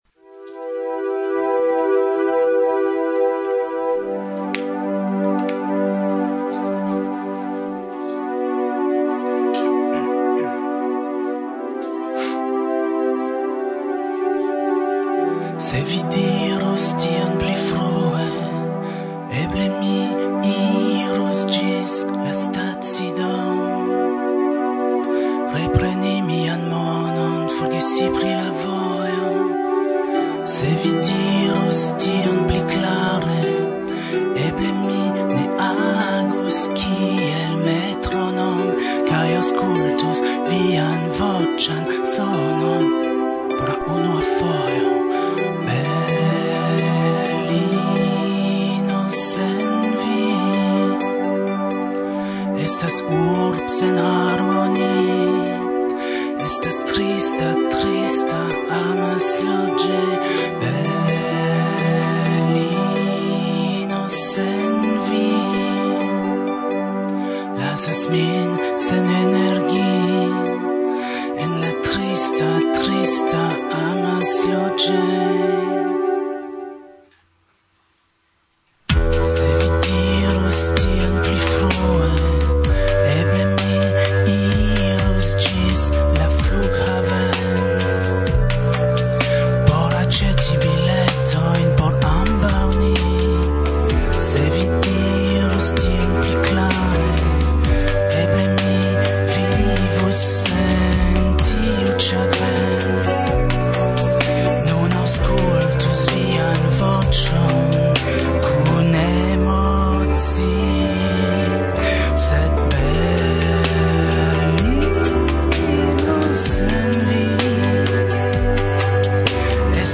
La kvara intervjuo…